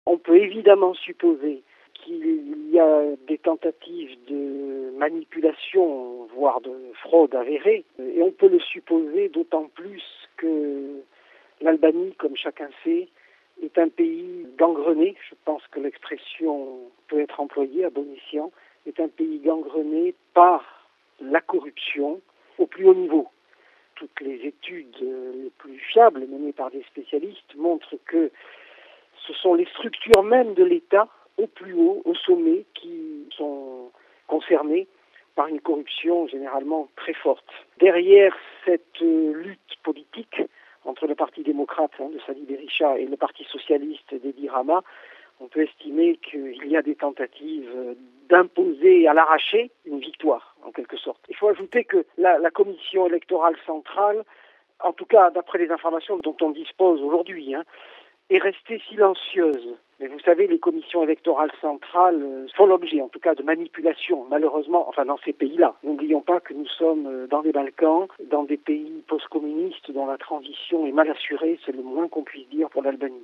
spécialiste des Balkans